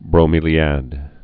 (brō-mēlē-ăd)